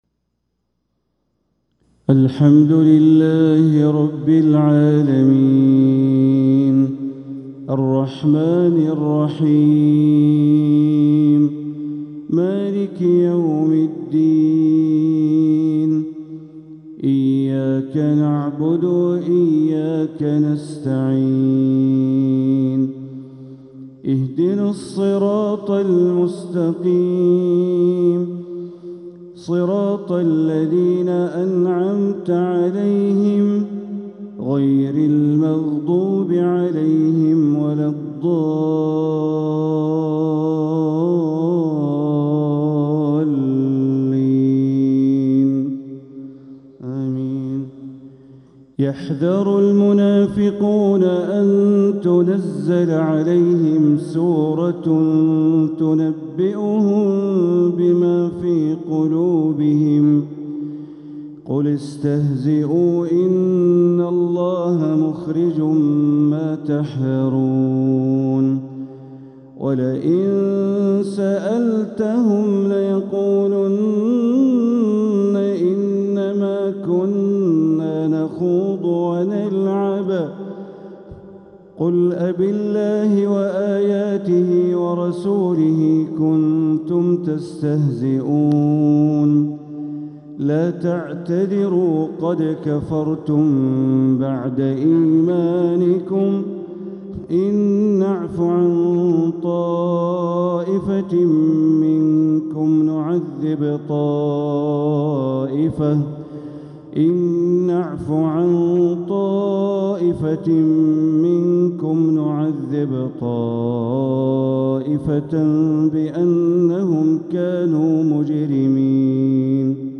تلاوة من سورة التوبة ٦٤-٧٤ | فجر الخميس ١٧ ربيع الآخر ١٤٤٧ > 1447هـ > الفروض - تلاوات بندر بليلة